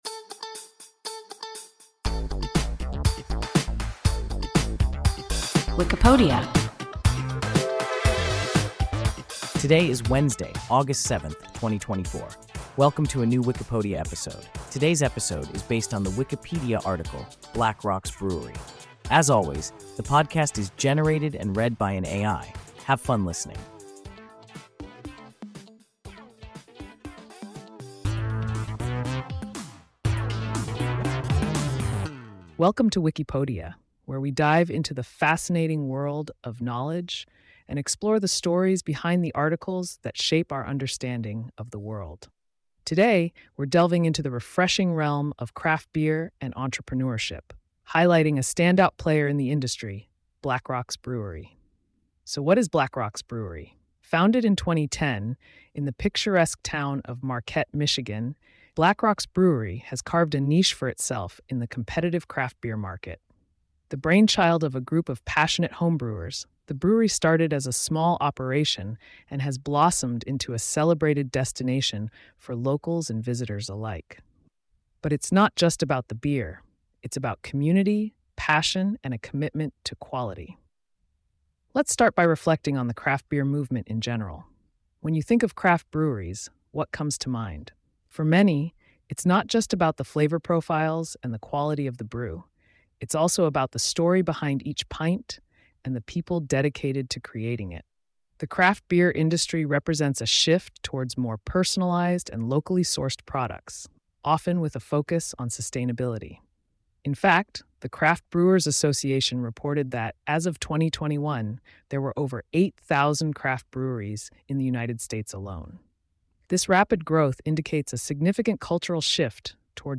Blackrocks Brewery – WIKIPODIA – ein KI Podcast